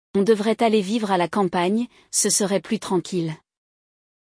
No episódio de hoje, vamos acompanhar um diálogo entre um casal que está planejando se mudar para o campo.
Durante o episódio, você ouvirá um diálogo entre nativos, seguido de explicações detalhadas e momentos para você repetir as palavras e treinar sua pronúncia.